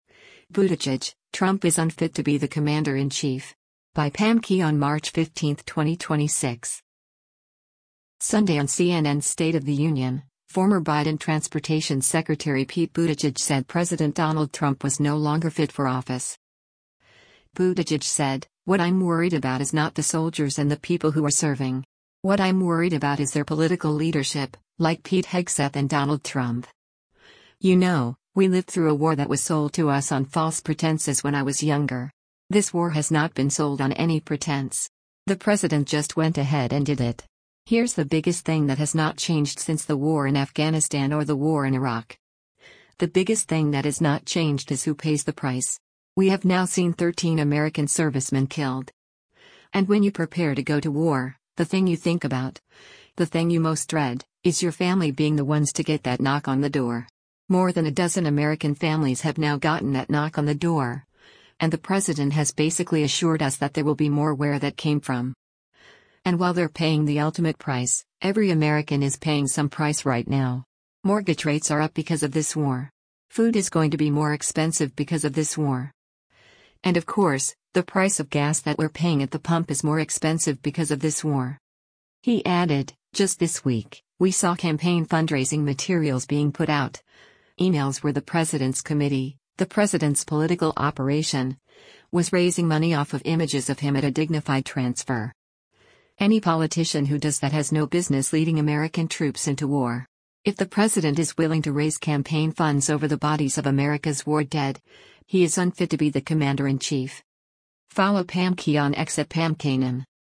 Sunday on CNN’s “State of the Union,” former Biden Transportation Secretary Pete Buttigieg said President Donald Trump was no longer fit for office.